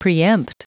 Prononciation du mot preempt en anglais (fichier audio)
Prononciation du mot : preempt